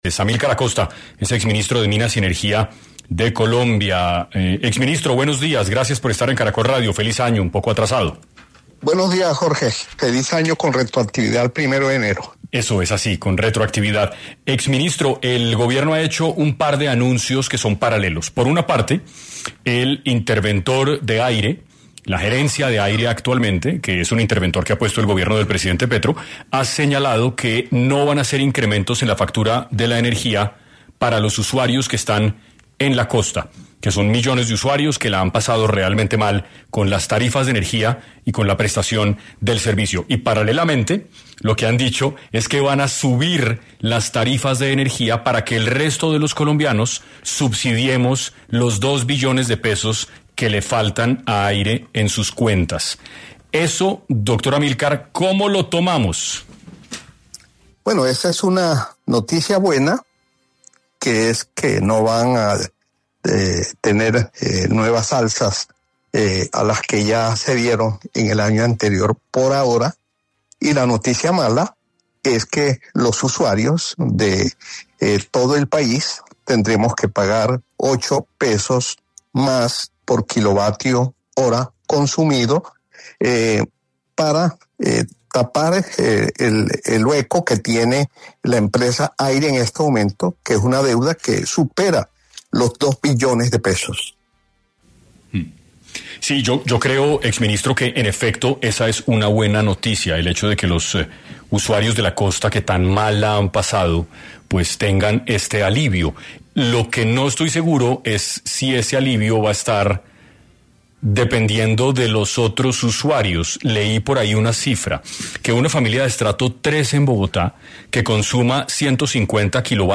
En 6 AM estuvo Amylkar Acosta Medina, exministro de Minas y Energía de Colombia, quien explicó varios detalles acerca del incremento en los recibos de luz en el país y algunas irregularidades.